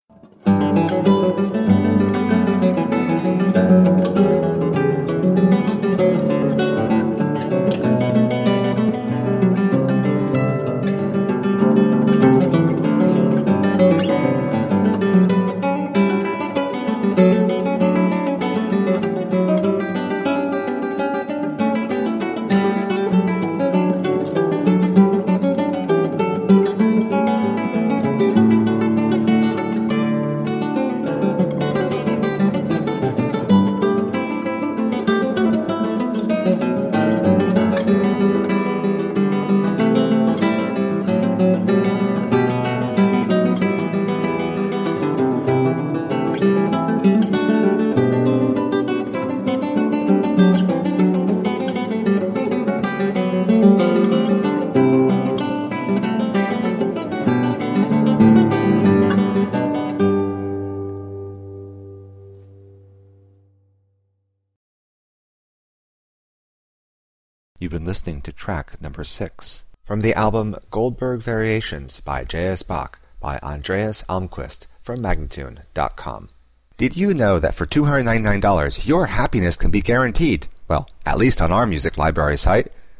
Elegant classical guitar.